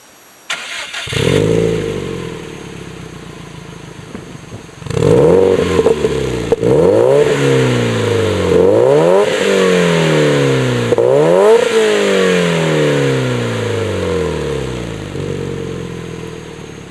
やっぱり等長エキマニになっていると静かに感じます。
麦マニは甲高い音だったのに対し、ノーマルは若干低めの音。
ノーマルエキマニ&RM-01A typeTi.aif